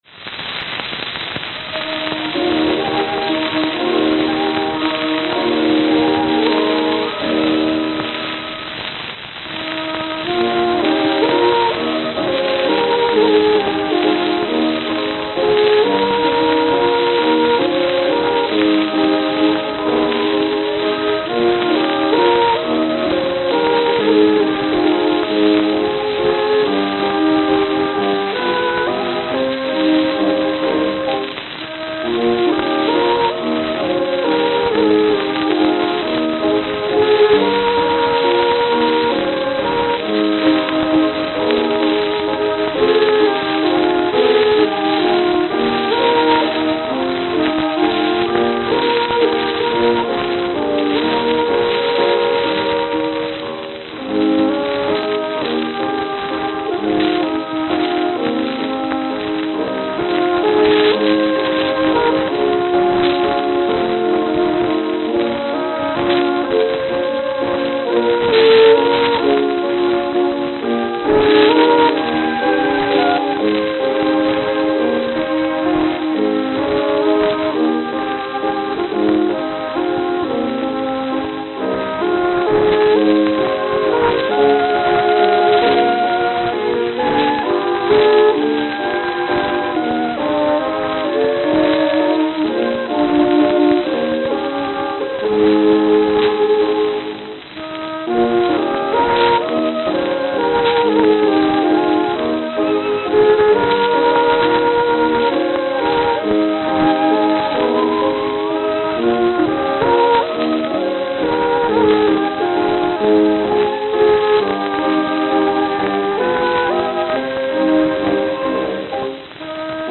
saxophone
Note: Extremely worn.